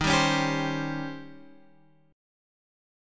D#13 chord